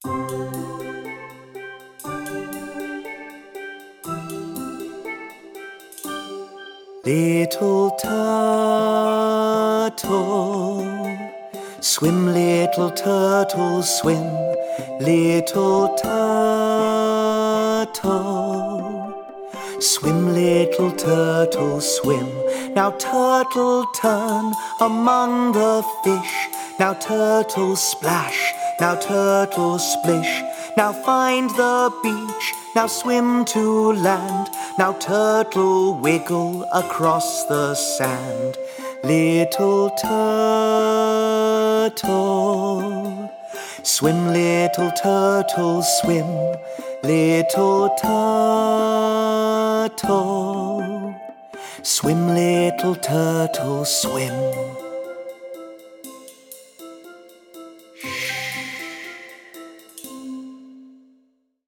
A tune from the Caribbean where the swimming of a little turtle mingles with the gentle rhythm of lapping waves.
The music features steel pans and the accompaniment for chord instruments has chords that are very suitable for ukuleles.
Guide vocal
little_turtle_vocal.mp3